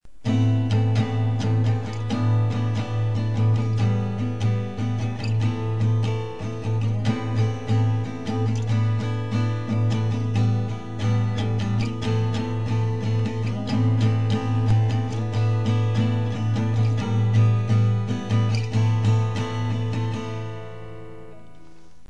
Красива, но не узнаю((